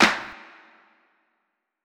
TC2 Clap3.wav